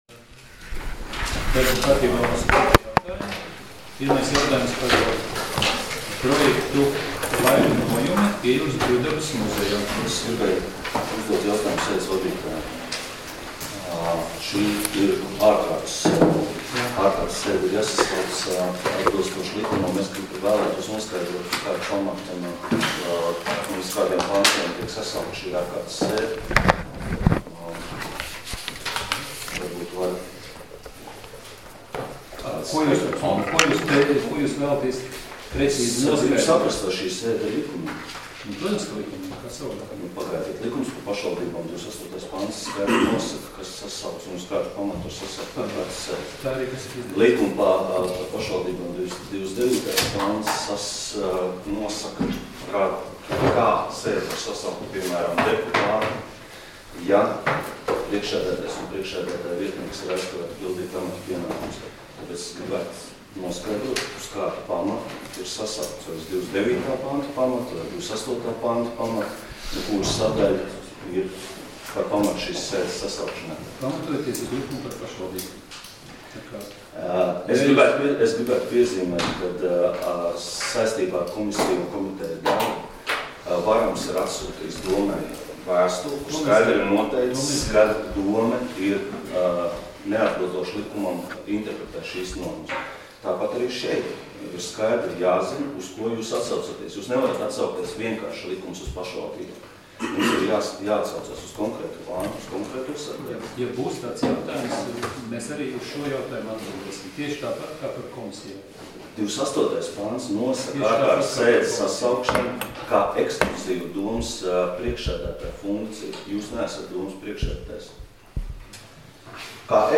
Domes sēdes 23.02.2018. audioieraksts